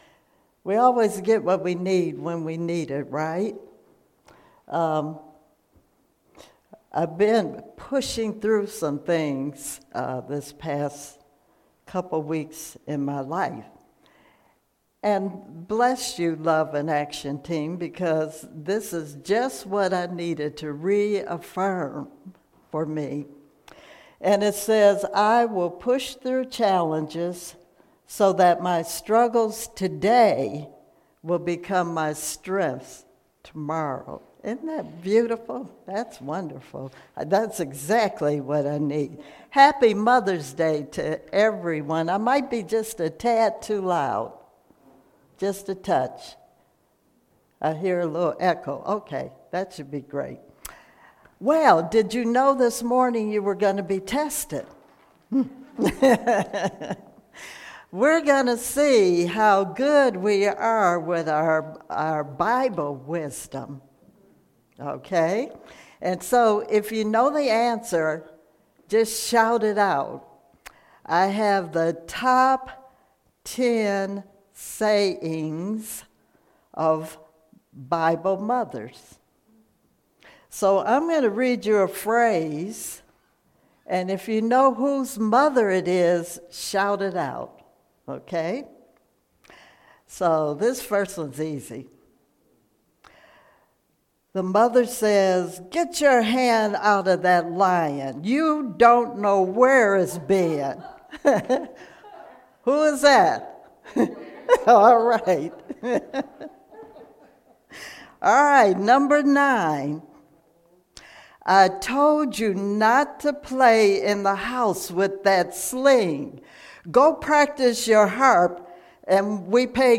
Spiritual Leader Series: Sermons 2019 Date